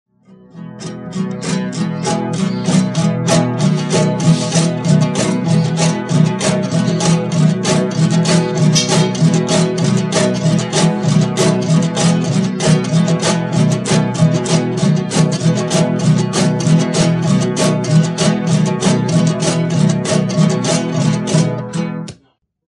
Viola-de-cocho
Viola feita de uma peça única de madeira, cujo tampo pode ou não possuir pequeno orifício. Tem cinco cordas e é utilizada como instrumento de acompanhamento rítmico e harmônico.
violadecocho.mp3